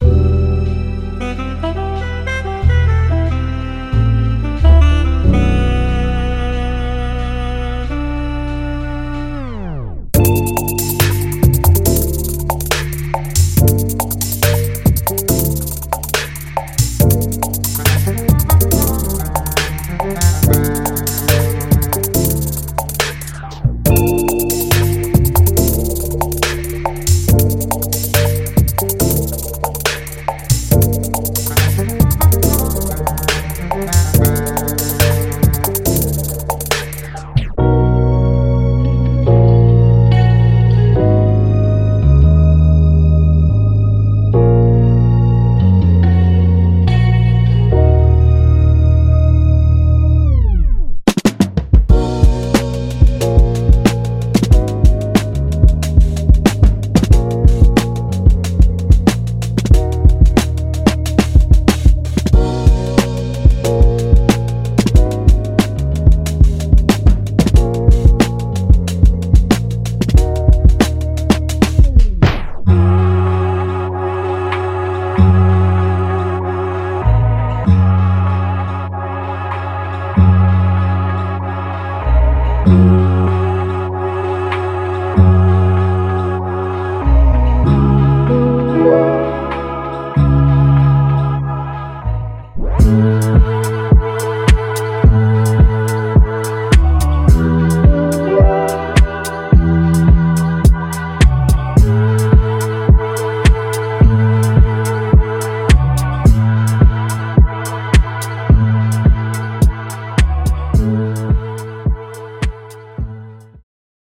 ● Vintage, dusty drums with a warm, analog feel
● Smooth chord progressions inspired by classic soul
● Saxophone loops that bring a touch of jazz and soul
● Rich melodic instrument loops perfect for layering
● Deep, groove-heavy basslines to anchor your tracks